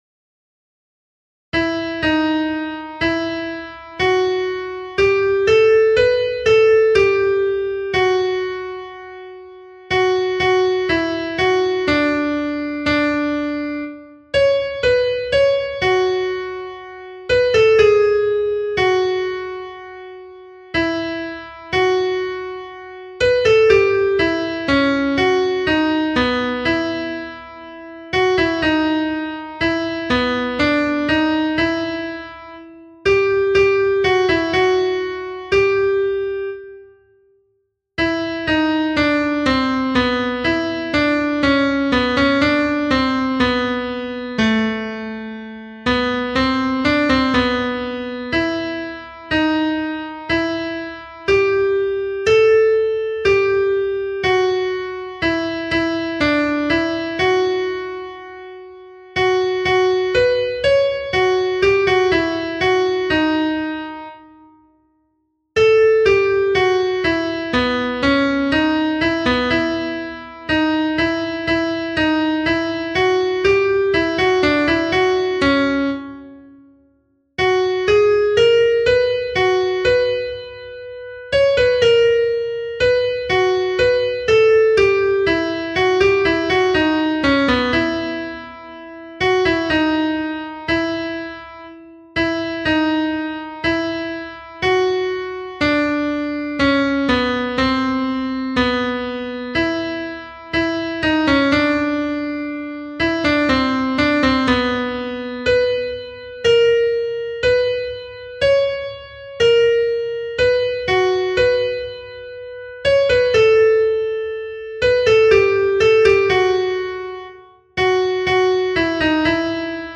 Alto (mp3)
Au tempo 60